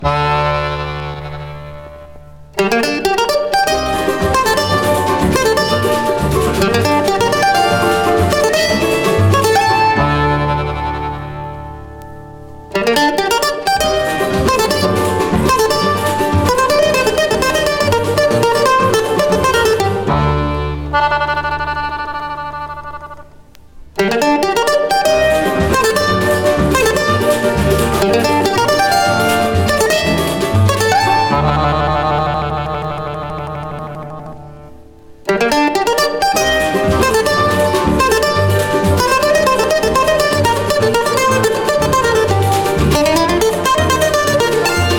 World, Latin, MPB, Samba, Choro　Brazil　12inchレコード　33rpm　Mono